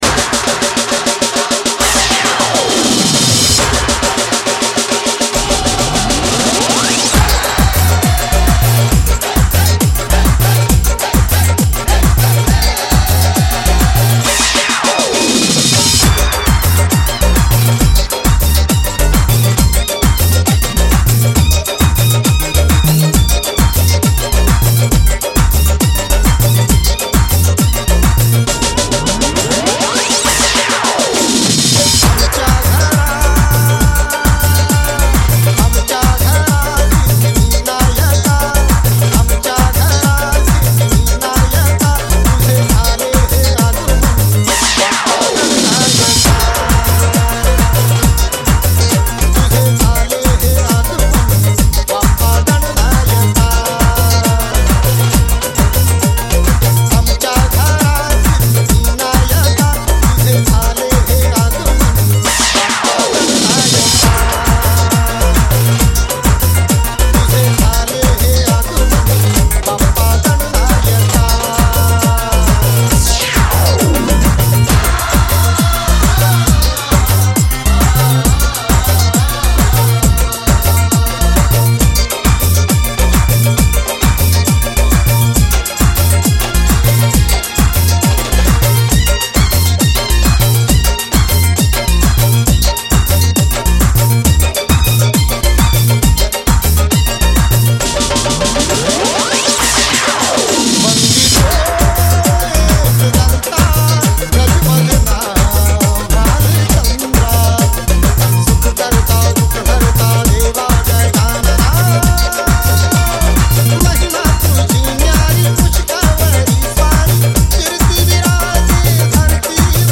D.J. Mix